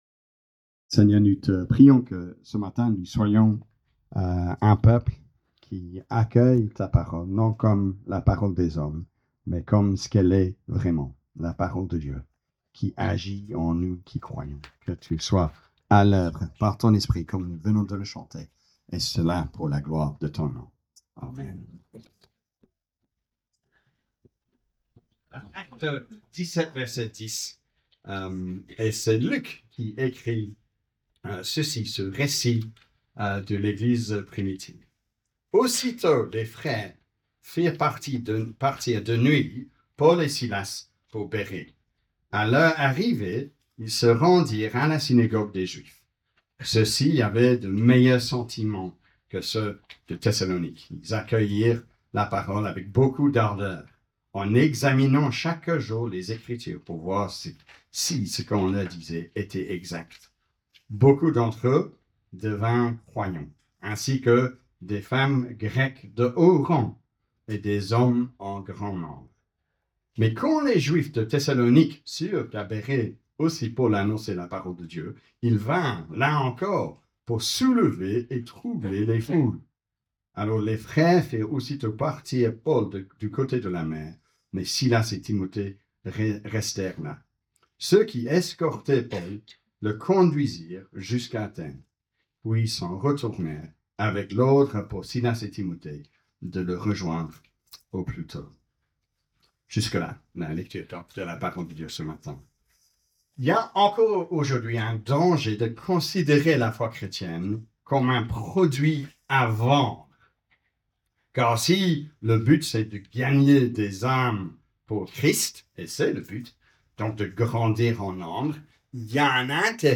Prédication du culte du 06 Avril 2025 de l’EPE Bruxelles-Woluwe